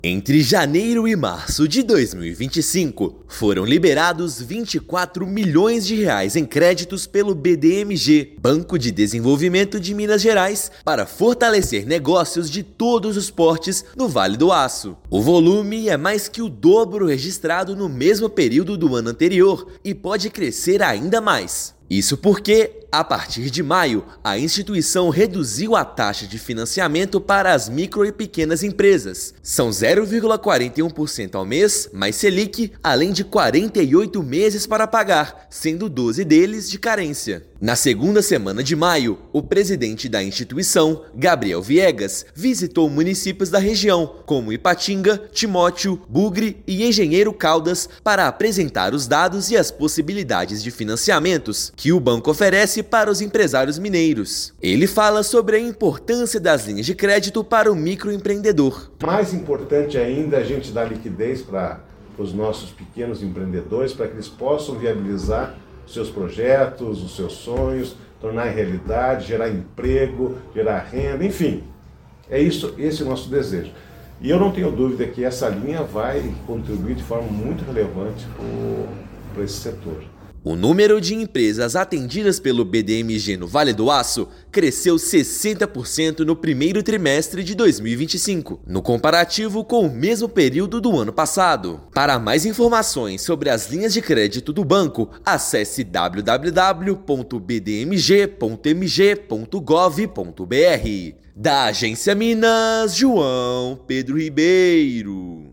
Destaque são pequenas empresas que, a partir de maio, terão taxa de financiamento reduzida no Banco de Desenvolvimento de Minas Gerais (BDMG). Ouça matéria de rádio.